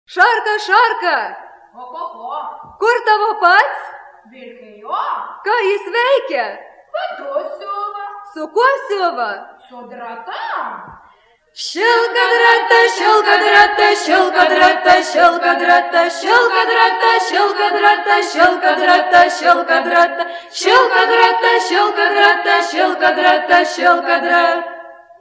FOLKLORE> DANCES> Games